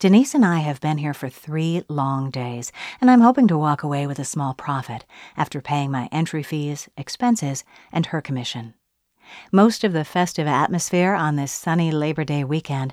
-Microphone Shure SM7B Dynamic Vocal Mic w/ Mic Boom Stand, Pop Filter
You should be working in Mono rather than struggling with two-channel stereo.
I got it to pass with relatively simple corrections. I made it louder and added very gentle noise reduction.